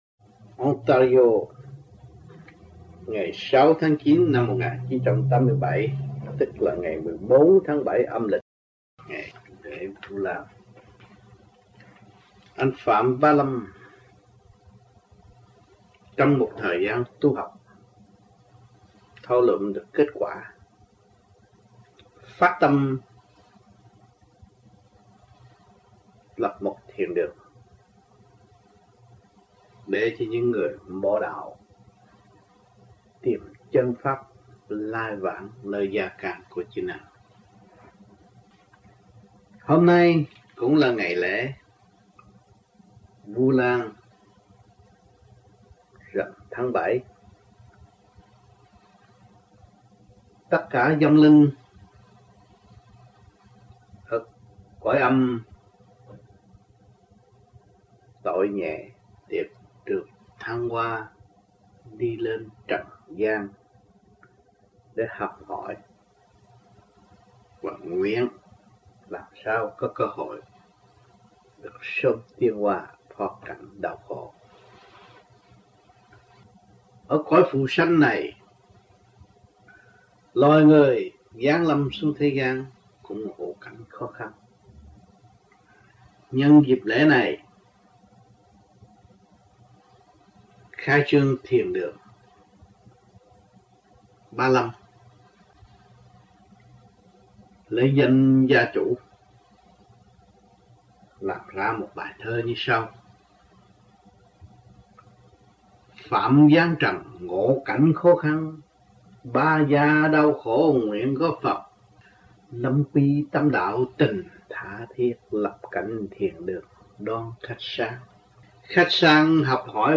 1987-09-06 - TORONTO - THUYẾT PHÁP THIỀN ĐƯỜNG PHẠM BÁ LÂM